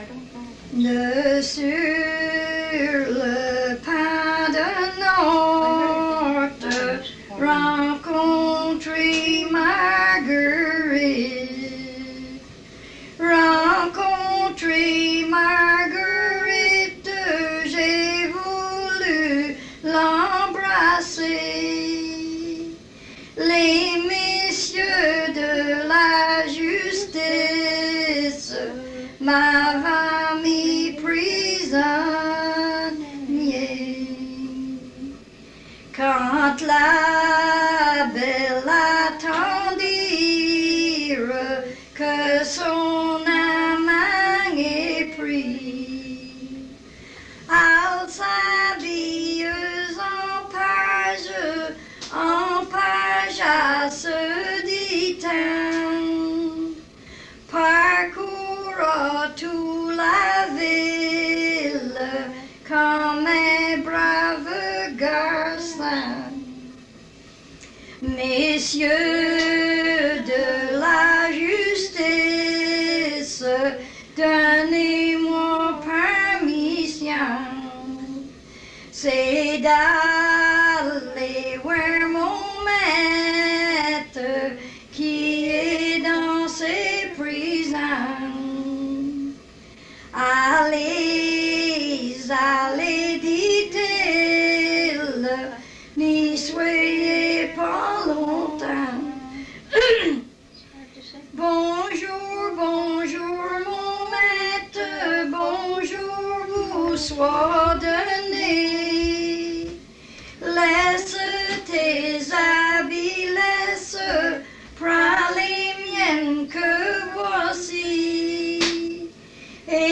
Chanson Item Type Metadata
Emplacement Upper Ferry